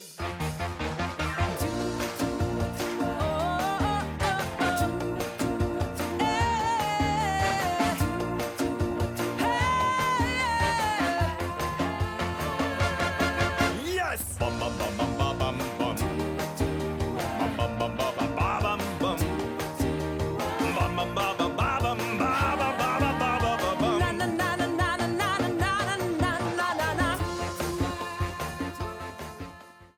A red streamer theme
Ripped from the game
clipped to 30 seconds and applied fade-out
Fair use music sample